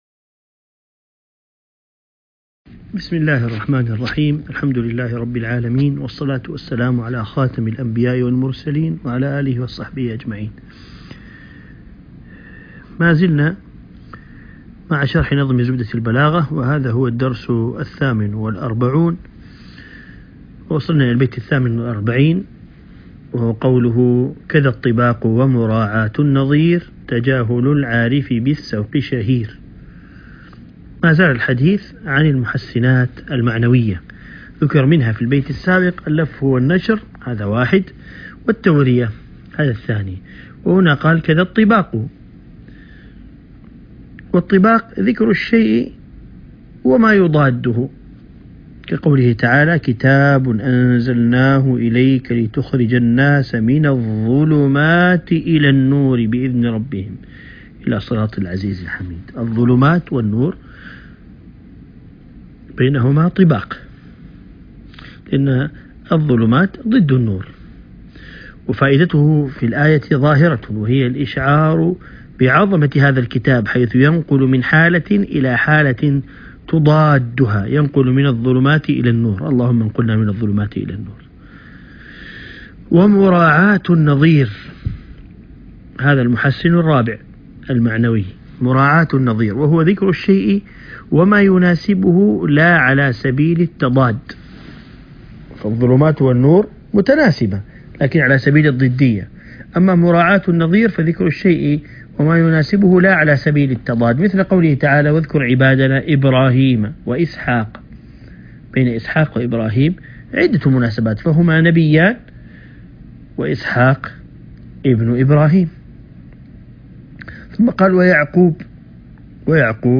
عنوان المادة الدرس ( 48) شرح نظم زبدة البلاغة